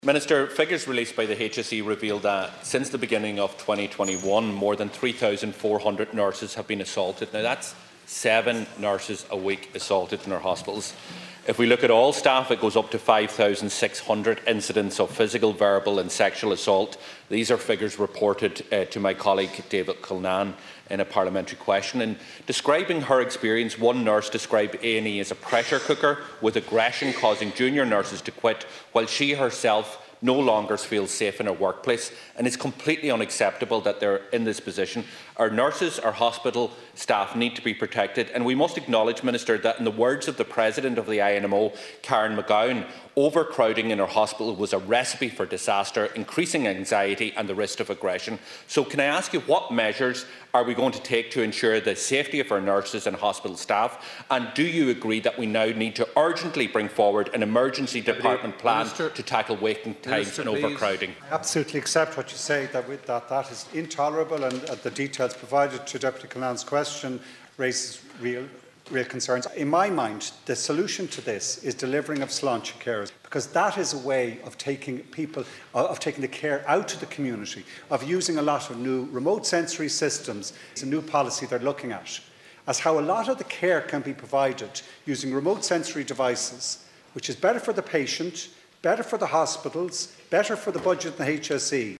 Green Leader and Environment MInister Eamon Ryan was responding to Donegal TD Pearse Doherty who told the Dail that since the starty of last year, thousands of nurses and other hospital staff have been assaulted.